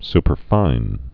(spər-fīn)